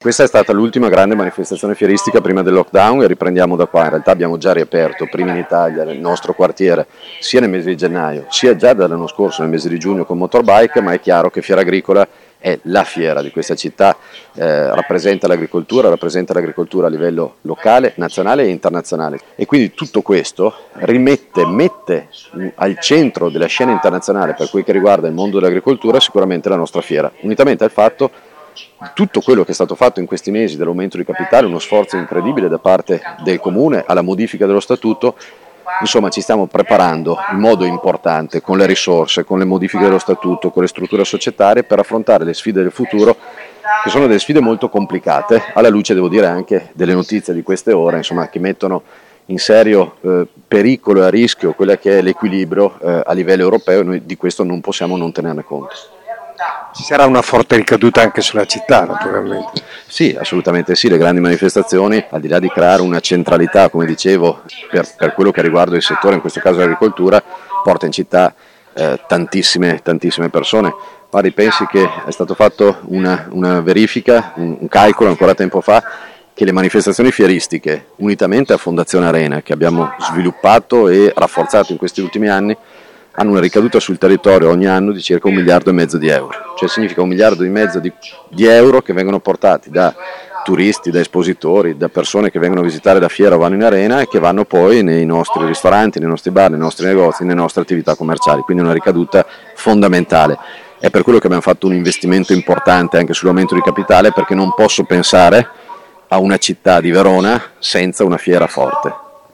Federico Sboarina sindaco di Verona: